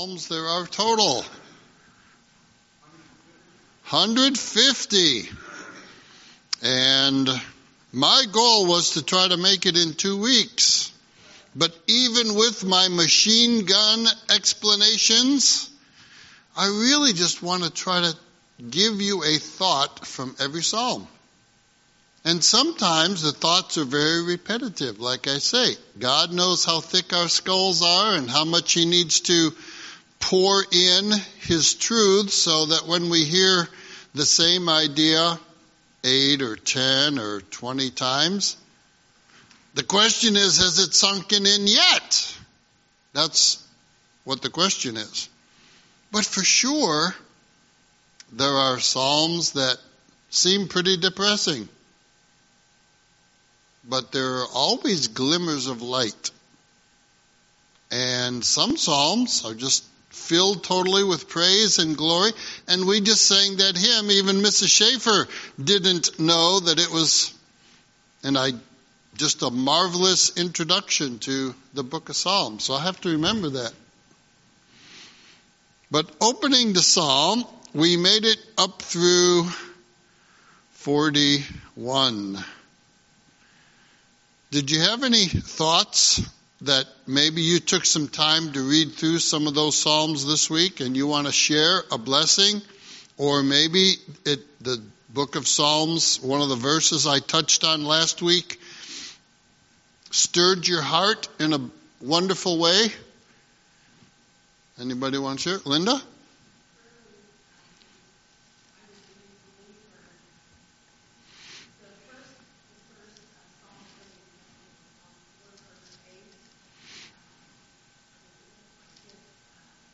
Sunday School Recordings